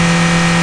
1 channel
MOTOR1.mp3